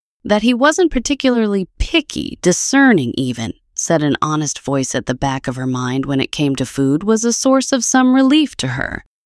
Cry_03.wav